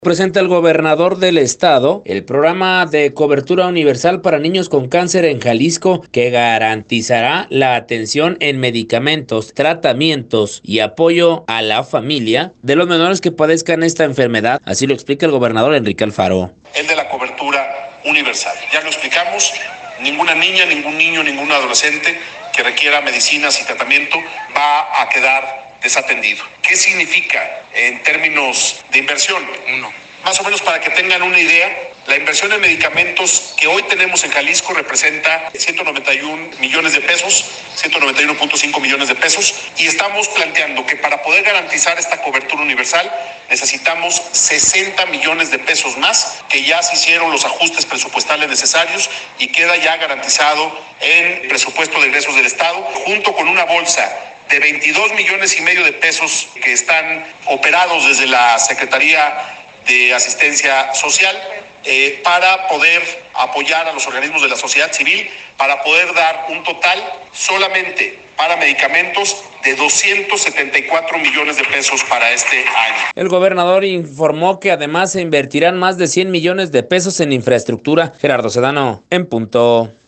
Presenta el gobernador del estado, Enrique Alfaro, el programa de cobertura universal para niños con cáncer en Jalisco, que garantizará la atención en medicamentos, tratamientos, y apoyo a las familias de los menores que padezcan esta enfermedad, así lo explica el gobernador, Enrique Alfaro;